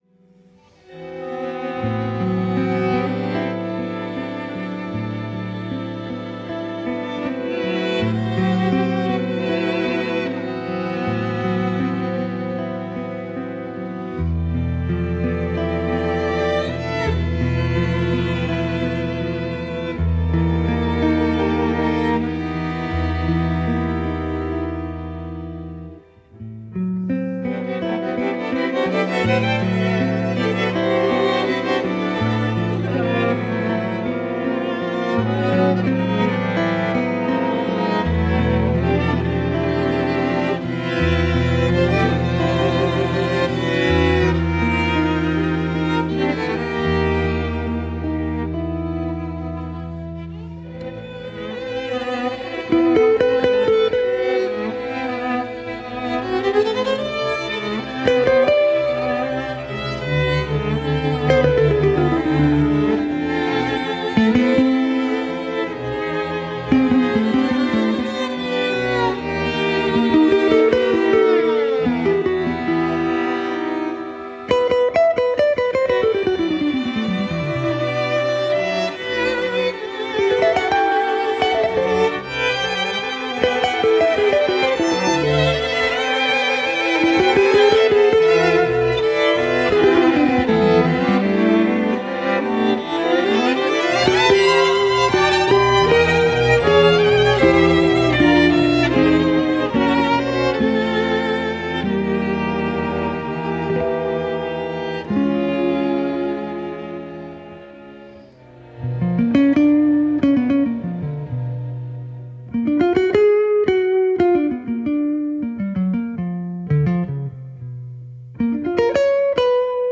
guitar
violin
viola
cello